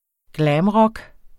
Udtale [ ˈglæːmˌʁʌg ]